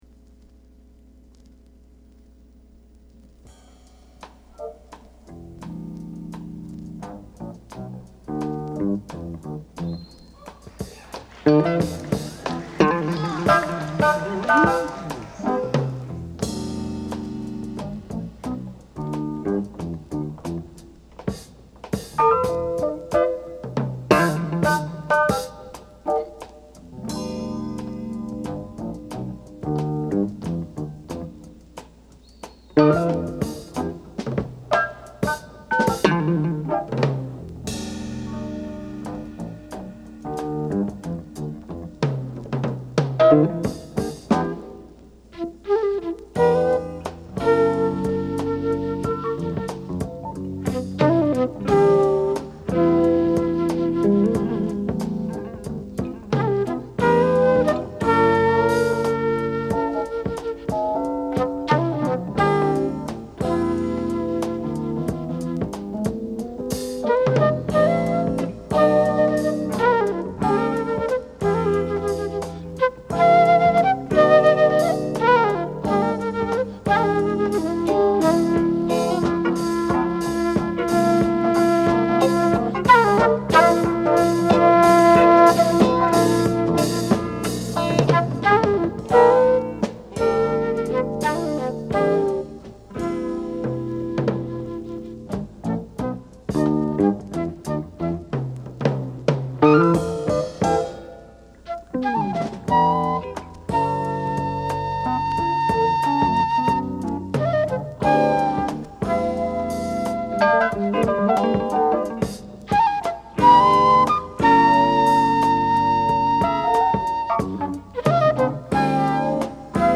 フルートが鳴った瞬間、空気ごと変わる。